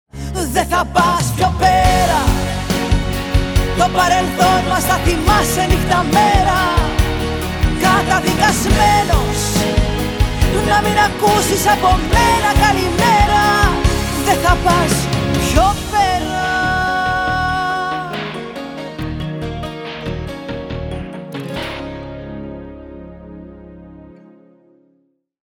Πρόκειται για μια δυναμική μπαλάντα